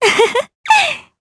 Laudia-Vox_Happy2_jp.wav